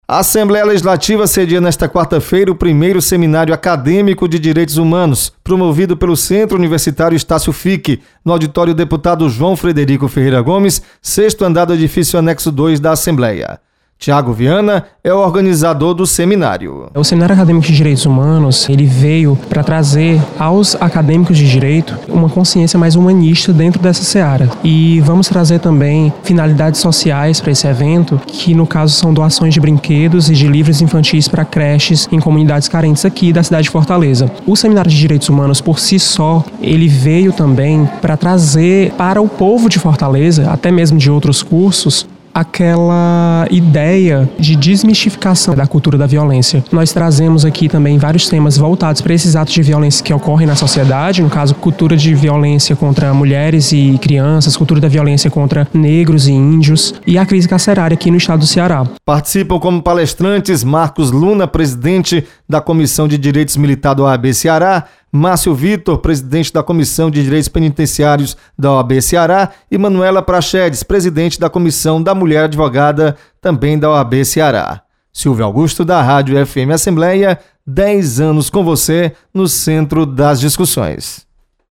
Você está aqui: Início Comunicação Rádio FM Assembleia Notícias Seminário